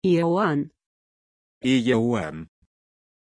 Pronunciación de Ieuan
pronunciation-ieuan-ru.mp3